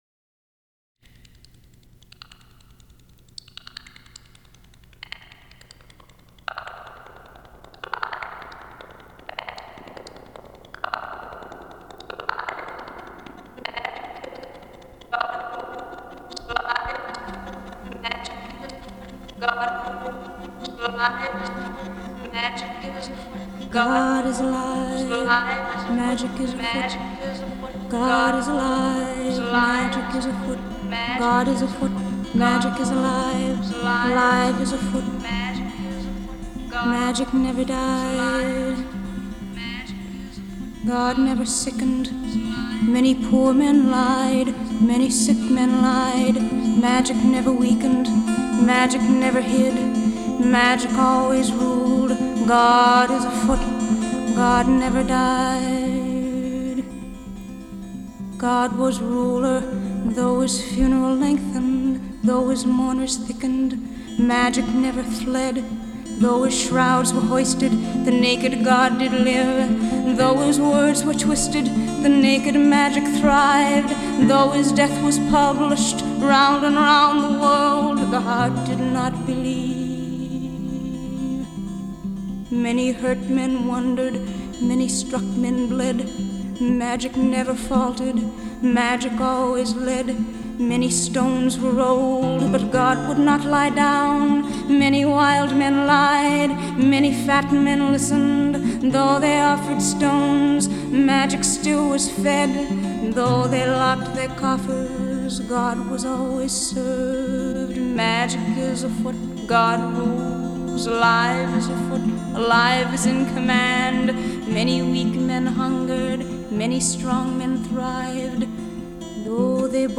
It's somber, and slightly eerie, jazz.